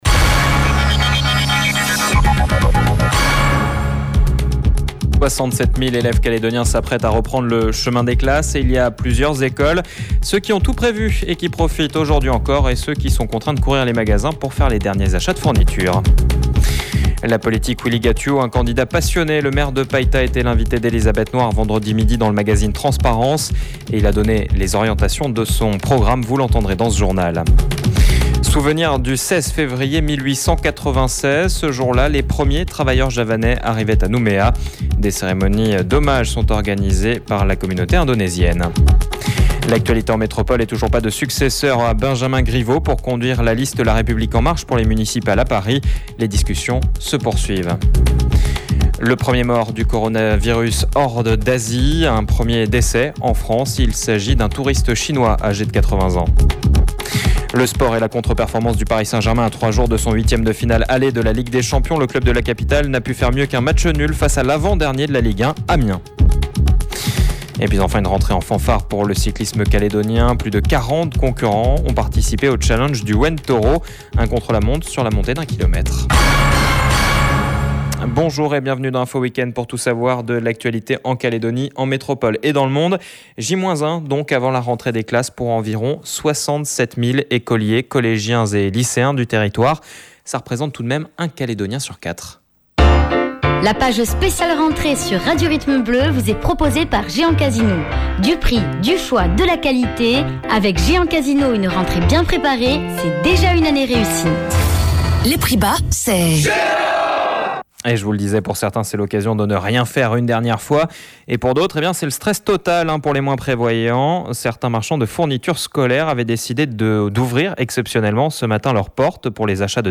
JOURNAL : DIMANCHE 16/02/20 (MIDI)